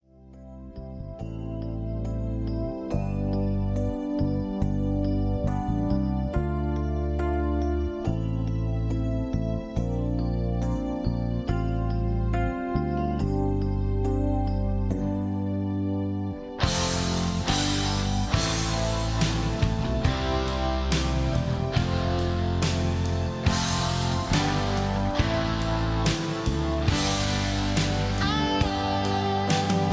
(Key-C) Karaoke Mp3 Backing Tracks
karaoke